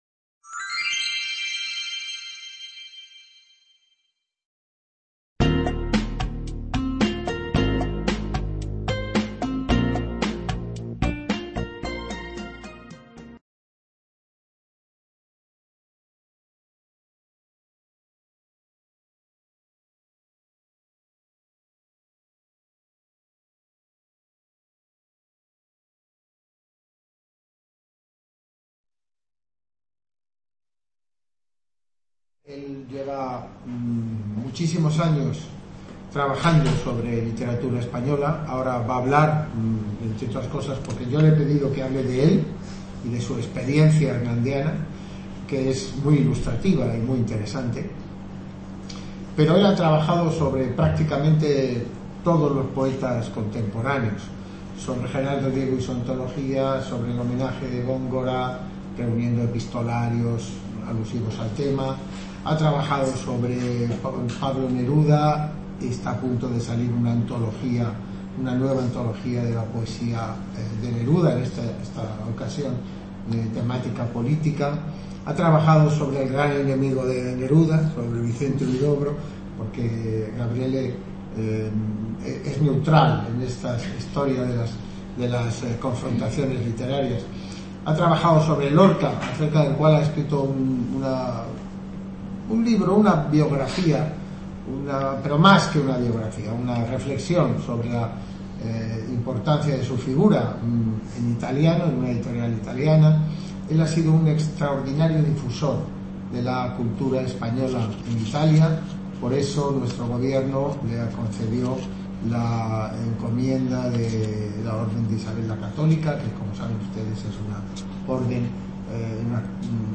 ponencia